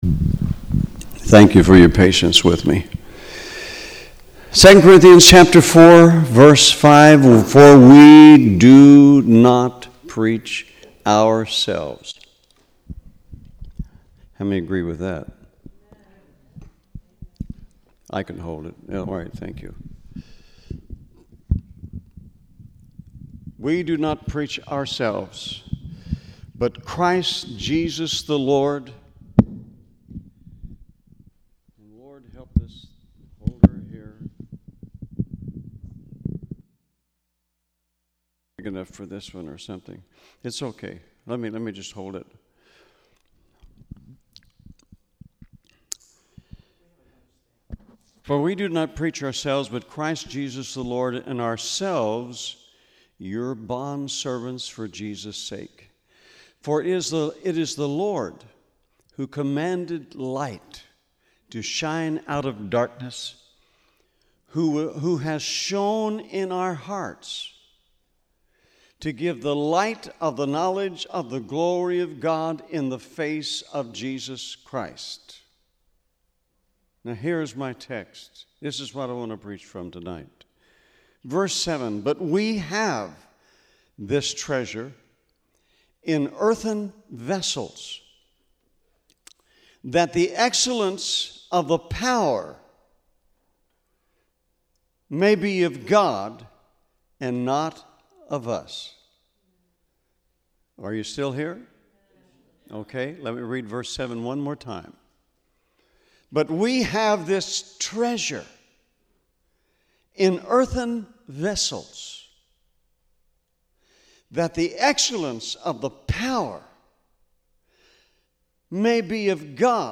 Special Sermons